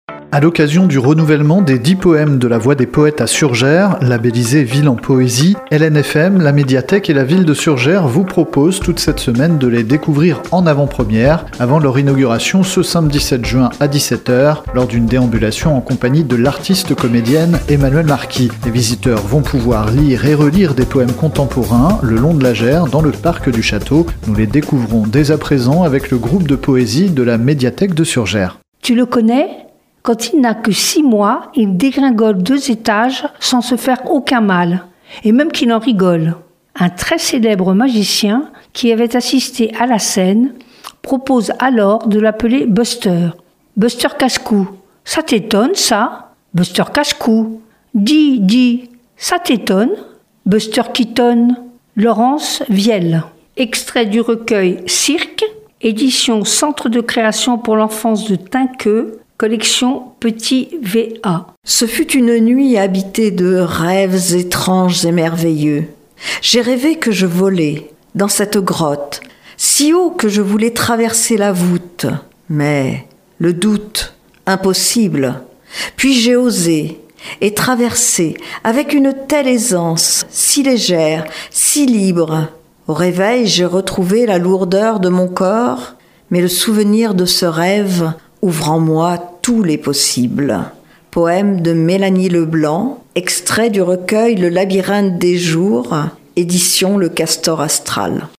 Les lectrices du groupe de poésie de la Médiathèque de Surgères, avec Frédérique Ragot (2e à droite), adjointe au maire.